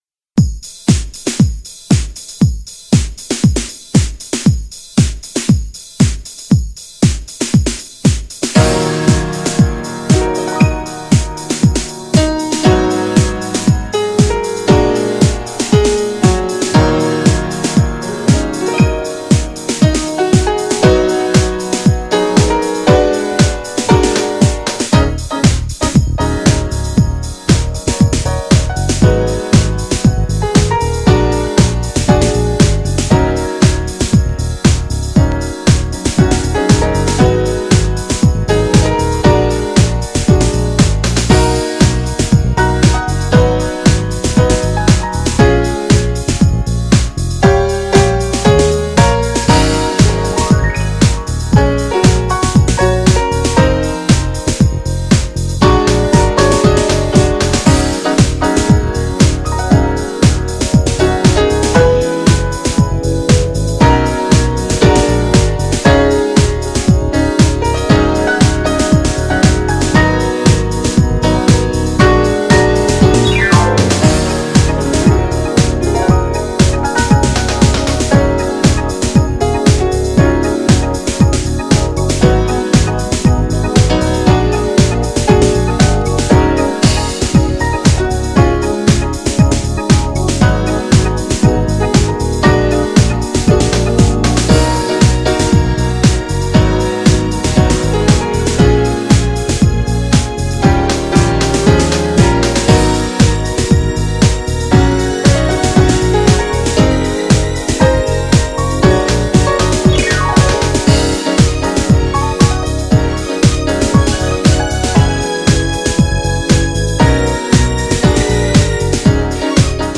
JAZZY INSTRUMENTALも最高!!
GENRE House
BPM 116〜120BPM